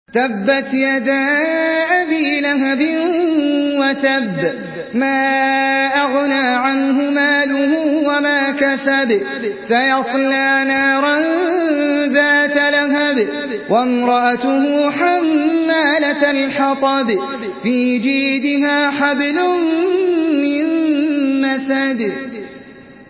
سورة المسد MP3 بصوت أحمد العجمي برواية حفص
مرتل حفص عن عاصم